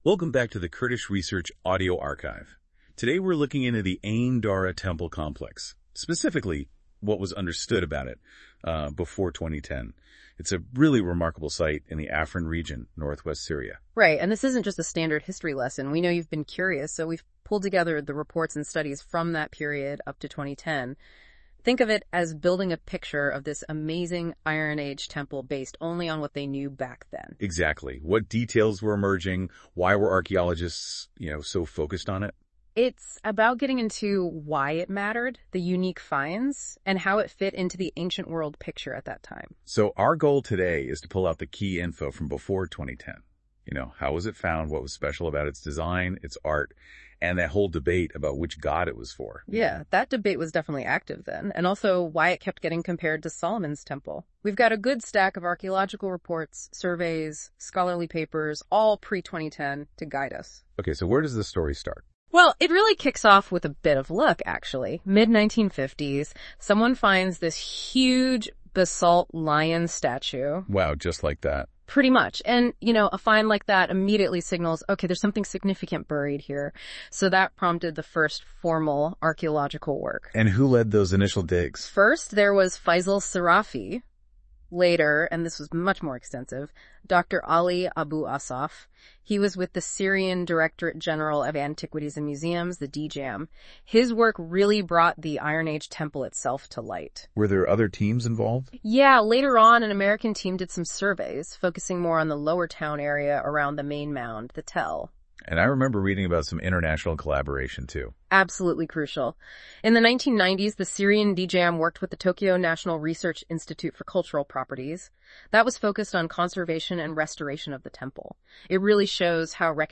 Note: This was made with AI research and AI audio output, and does not conform to academic standards.